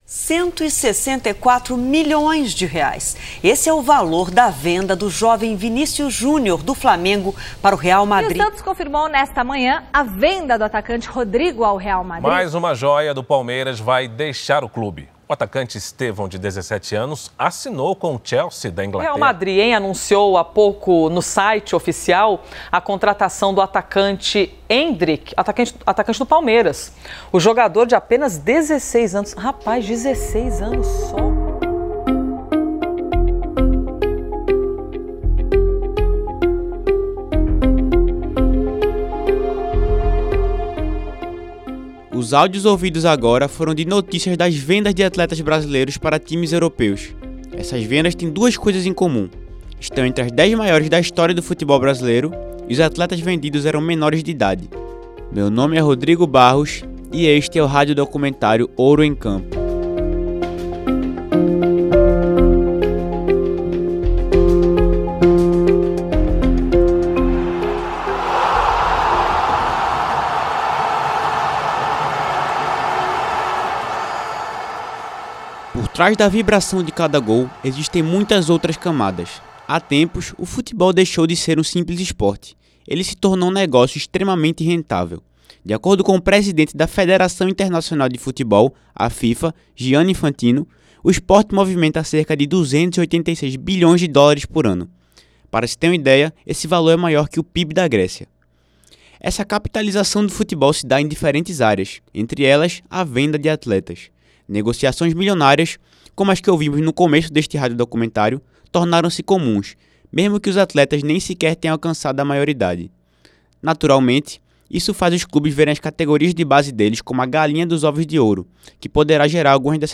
Radiodocumentário